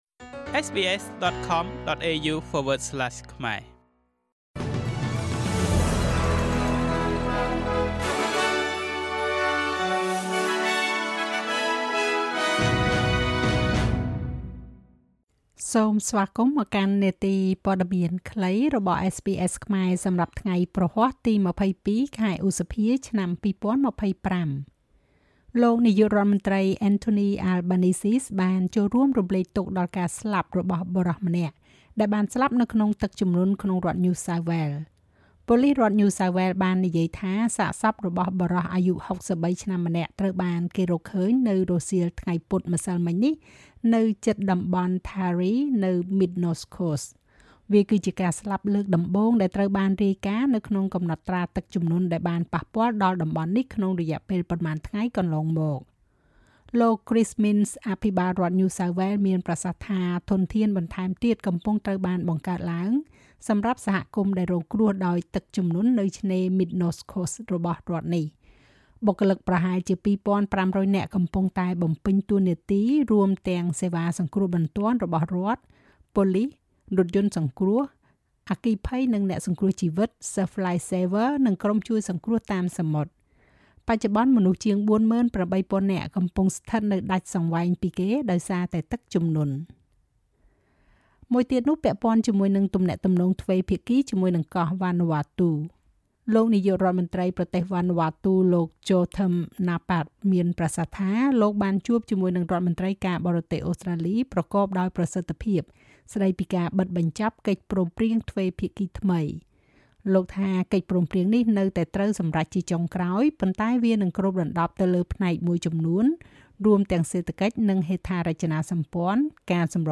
នាទីព័ត៌មានខ្លីរបស់SBSខ្មែរ សម្រាប់ថ្ងៃព្រហស្បតិ៍ ទី២២ ខែឧសភា ឆ្នាំ២០២៥